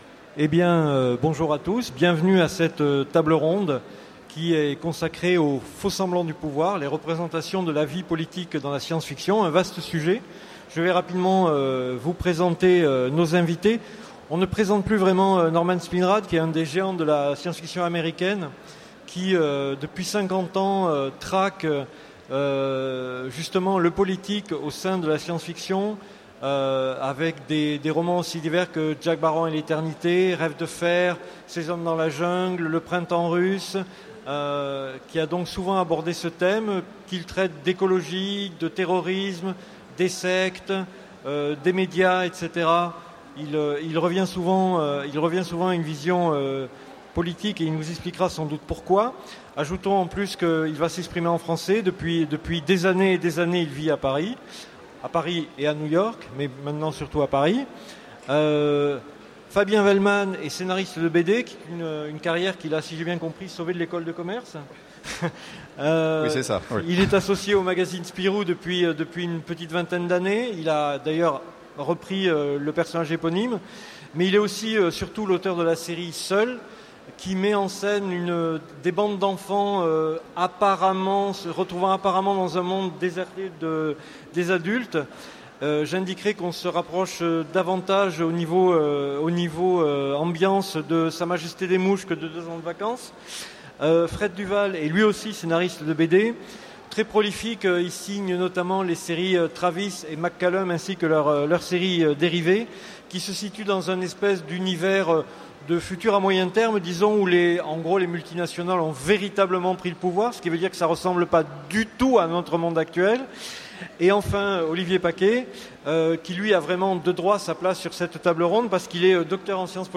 Utopiales 2015 : Conférence Les faux-semblants du pouvoir